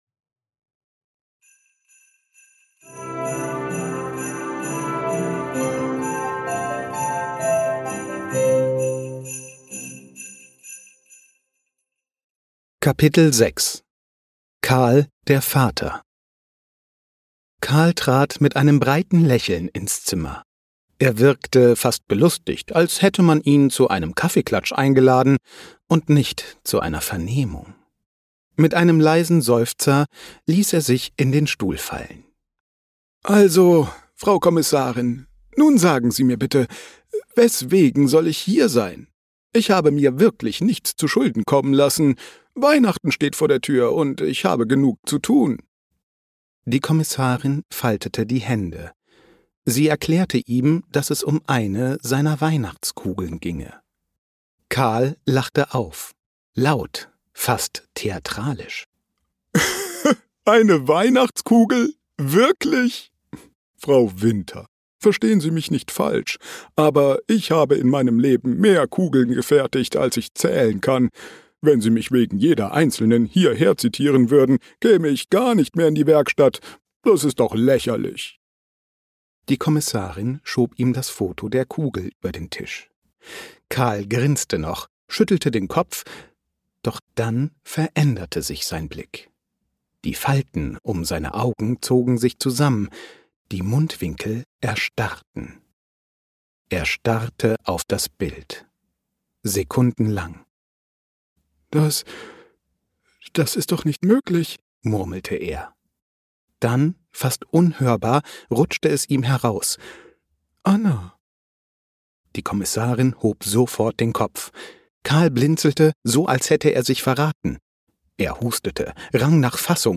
Kriminalgeschichte. Lass dich von acht verzaubernden Stimmen in die